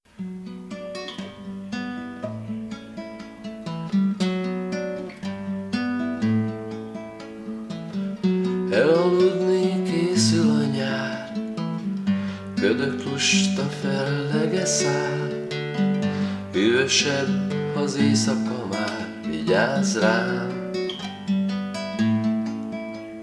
Attached file recorded by only cheap Olympus digital voice recorder.